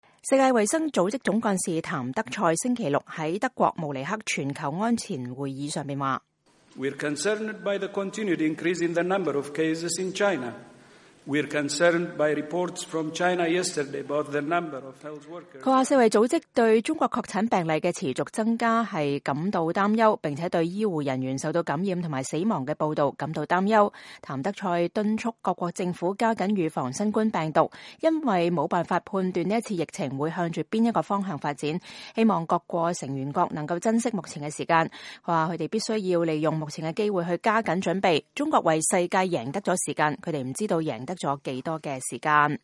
世界衛生組織總幹事譚德塞2月15日在德國慕尼黑全球安全會議上敦促各國繼續加緊防疫。